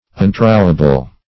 Untrowable \Un*trow"a*ble\, a.